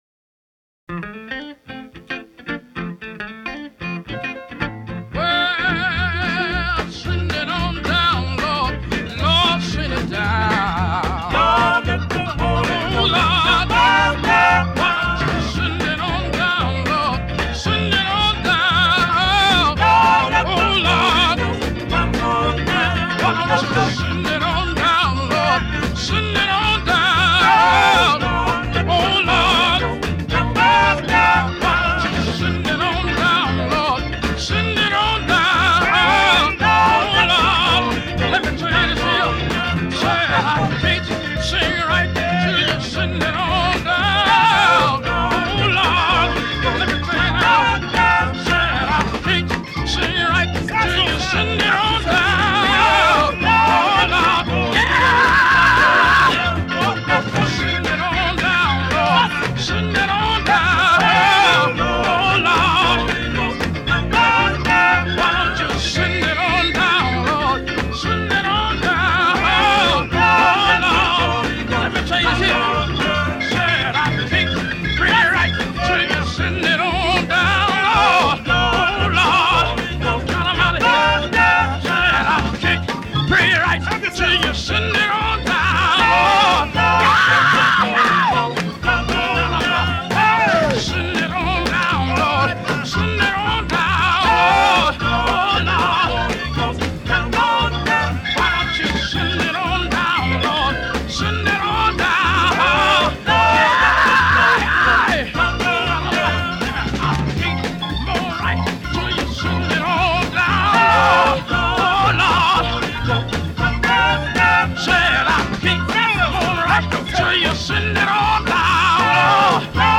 gospel songs in mp3 format